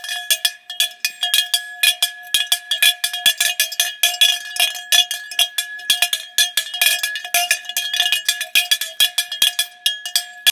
Kravský zvon so srdiečkami kov 12cm
Kravský zvon s dierovanými srdiečkami v zlatej farbe
Materiál: kov